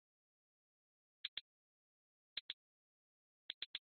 hi hat 13
描述：hi hat
Tag: 镲片 hi_hat Rides